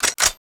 WEAP CLI03.wav